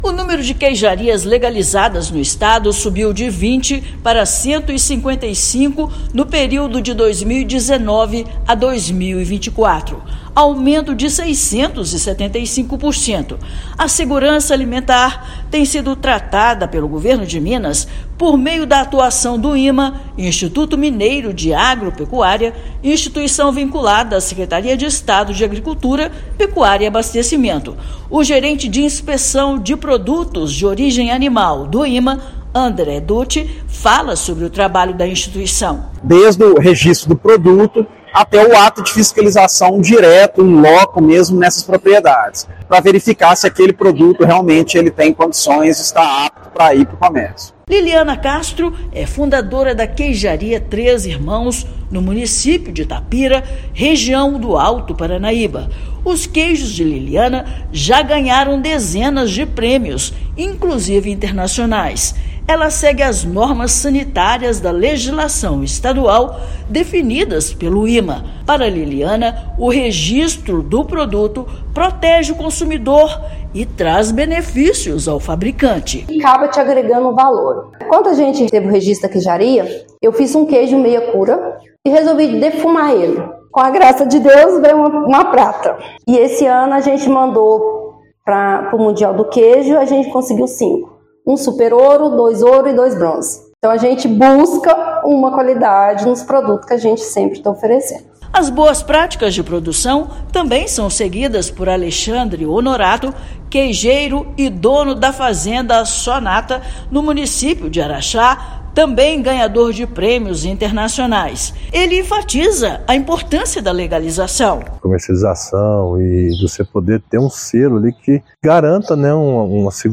Governo de Minas, por meio do Instituto Mineiro de Agropecuária, trabalha para garantir a legalização dos empreendimentos, valorizar a produção e abrir novos mercados para queijos artesanais mineiros. Ouça matéria de rádio.